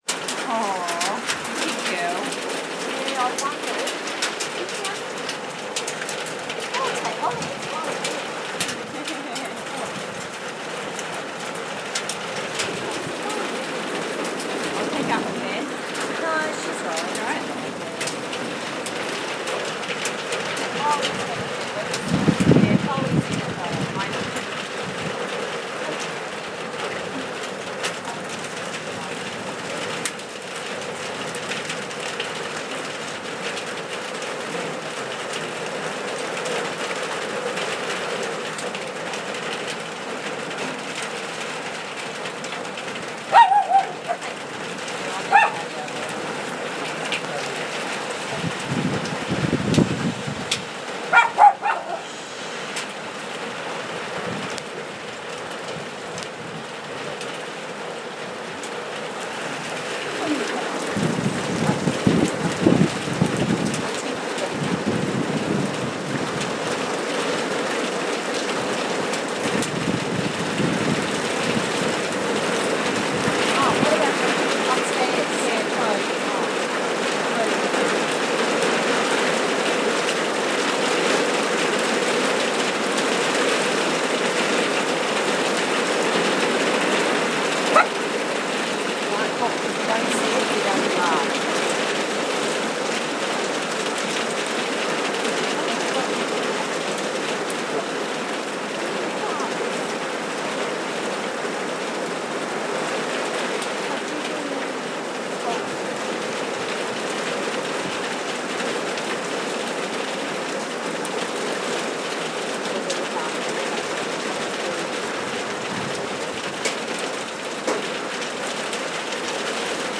first hailstorm of the season.